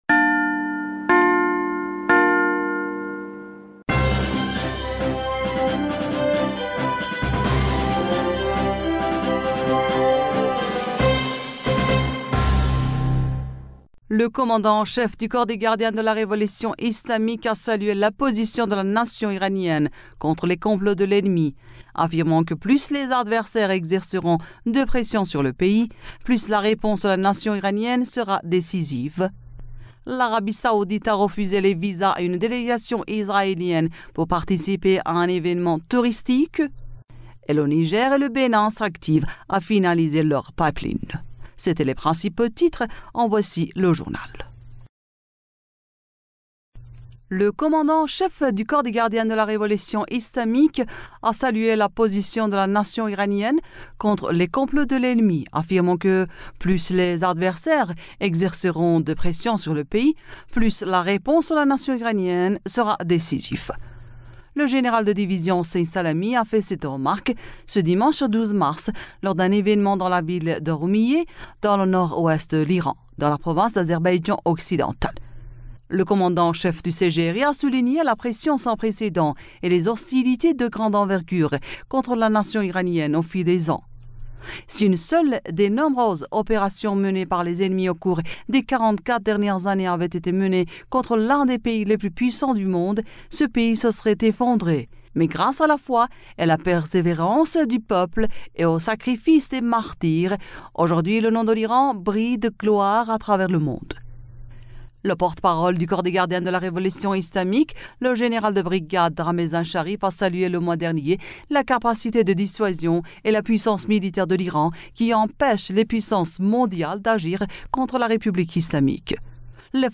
Bulletin d'information du 13 Mars